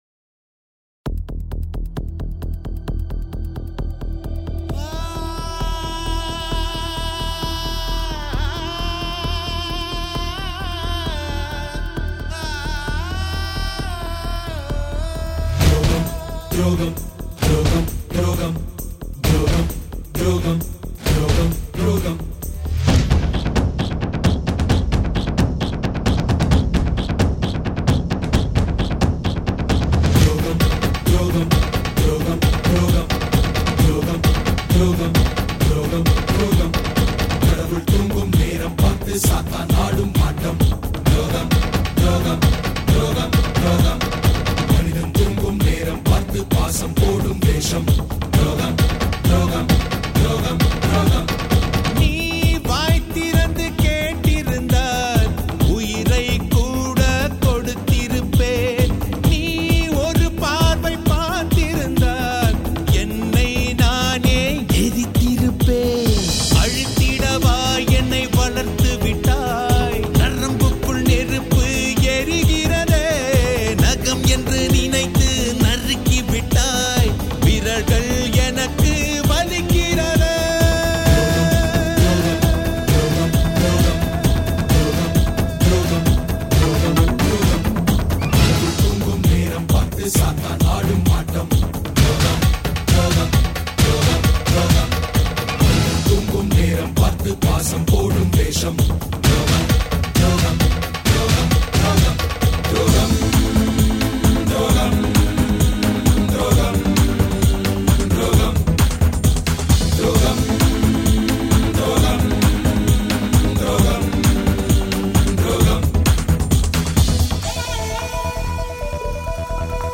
Tamil Movie Songs